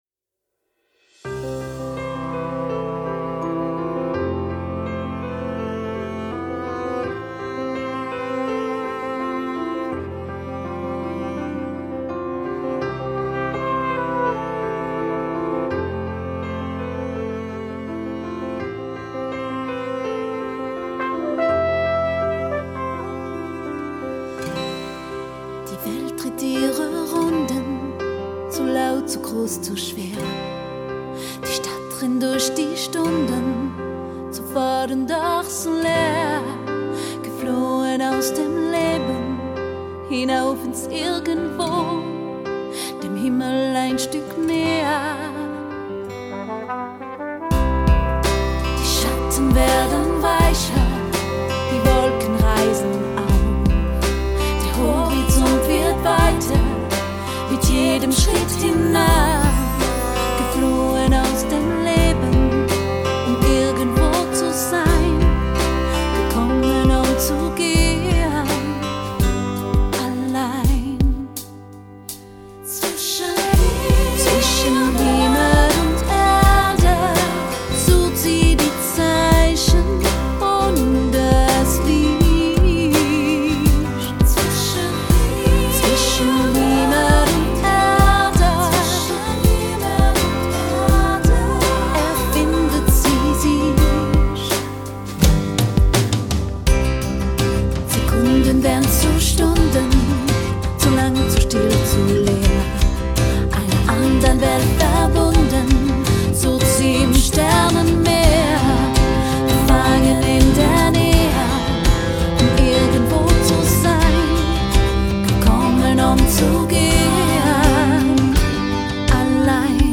Snippet 3 – Balladen